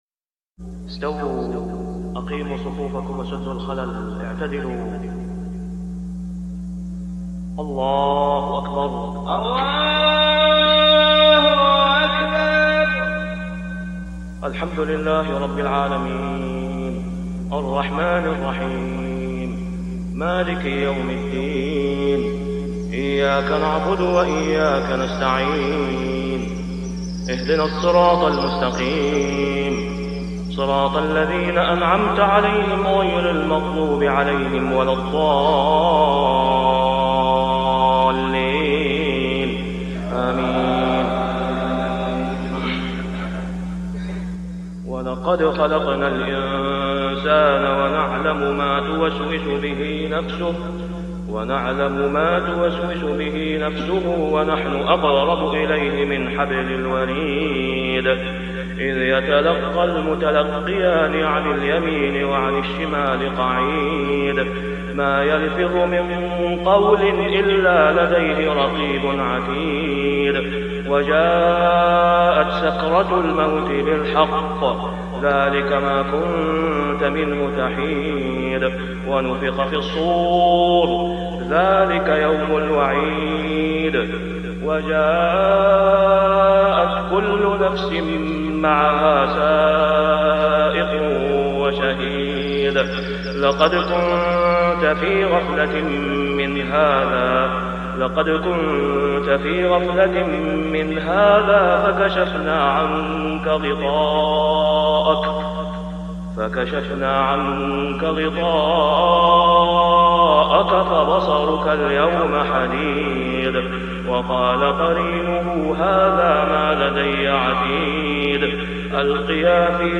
( صلاة الفجر و العام غير معروف ) | سورة قٓ 16-45 > 1423 🕋 > الفروض - تلاوات الحرمين